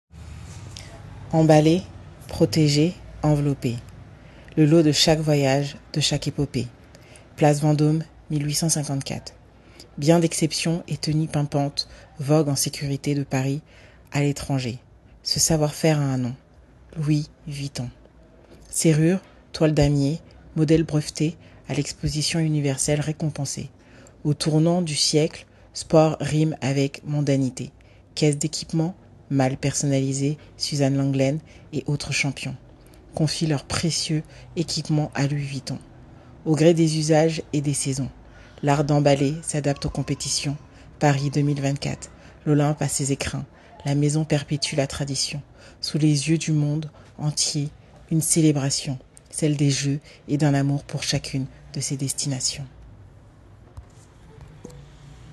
20 - 37 ans